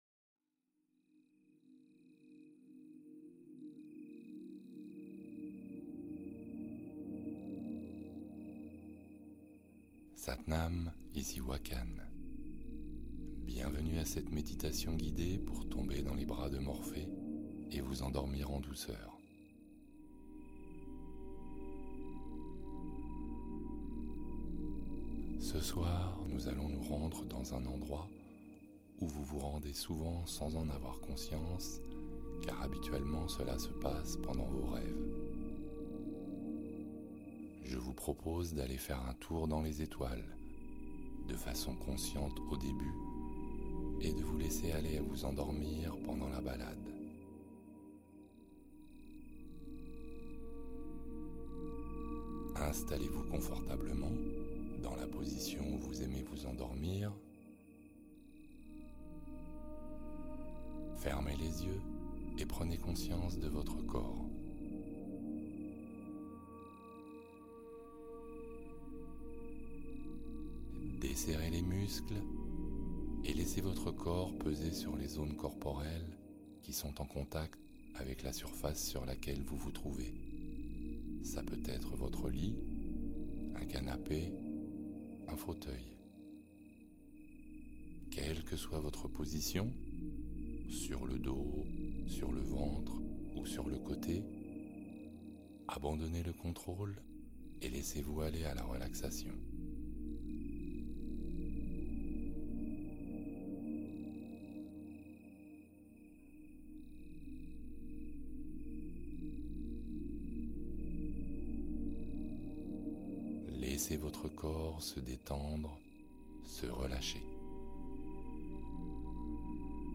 Sommeil Profond : Hypnose douce pour un endormissement rapide